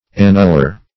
Annuller \An*nul"ler\, n.